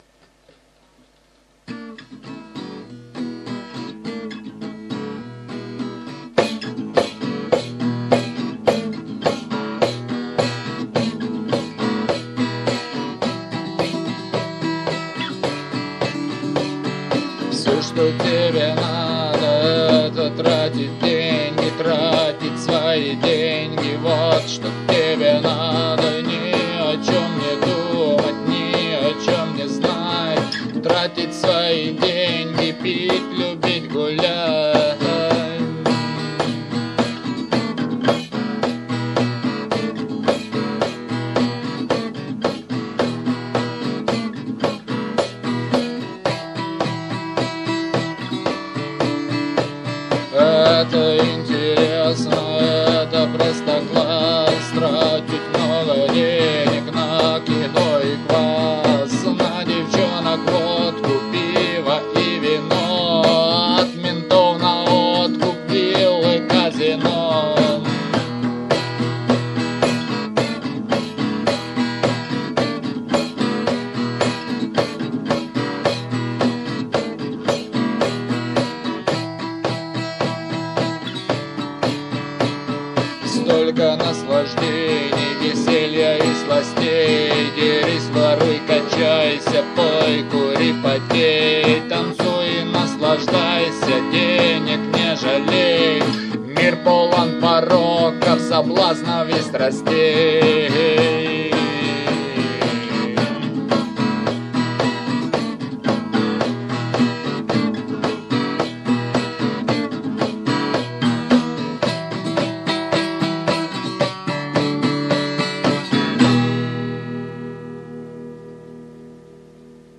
Рубрика: Поезія, Авторська пісня
Странно, но звучит как-то невесело...